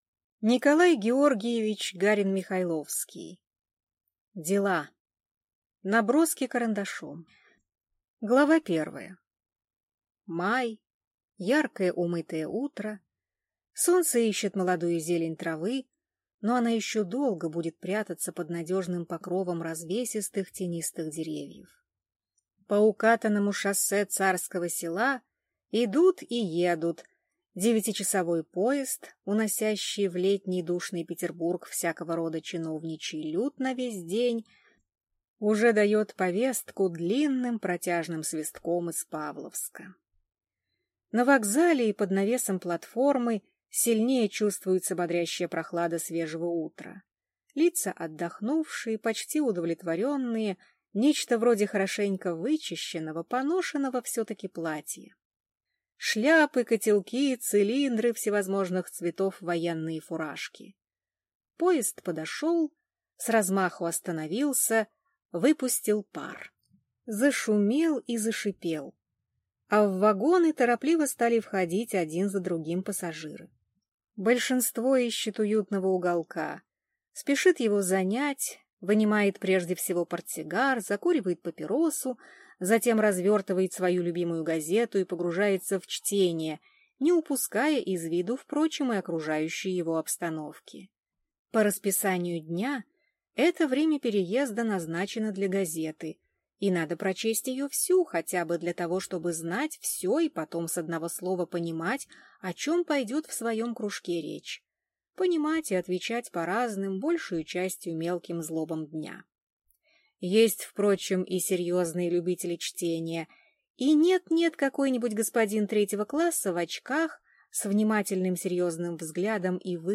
Аудиокнига Дела. Наброски карандашом | Библиотека аудиокниг